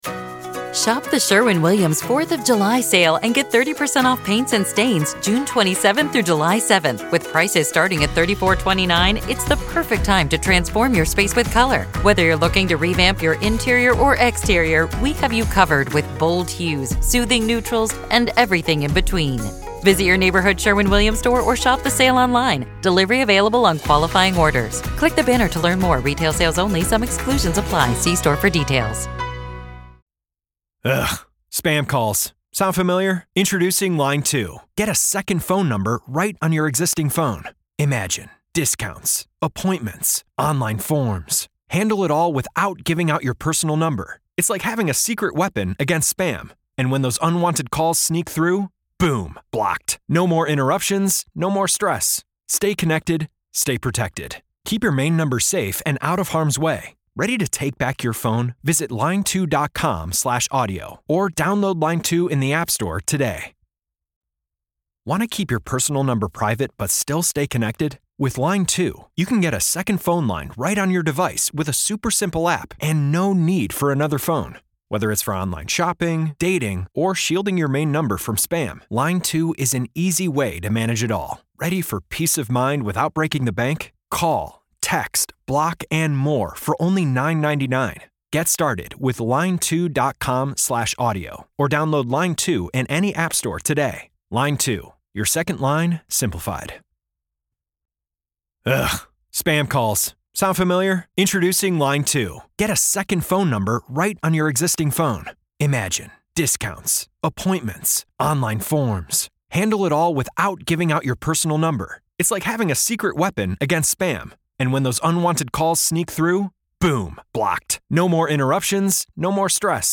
This is audio from the courtroom in the high-profile murder conspiracy trial of Lori Vallow Daybell in Arizona.